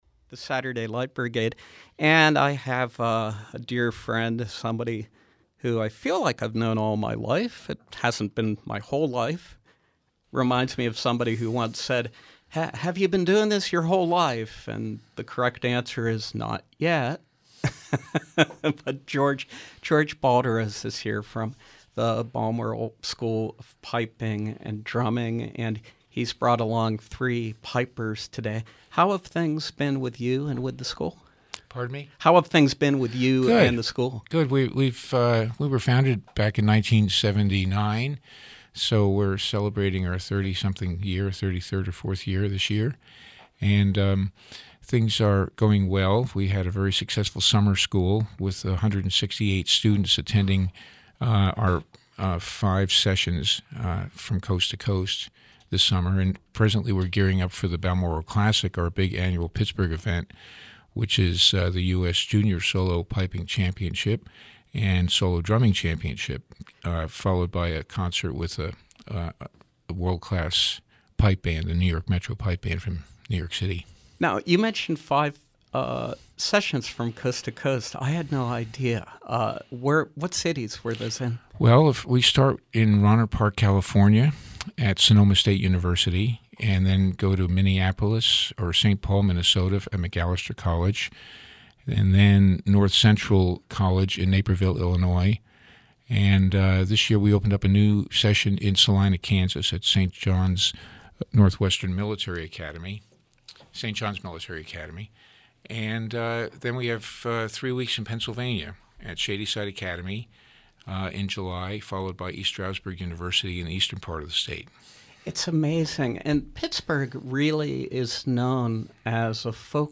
performering selections on bagpipes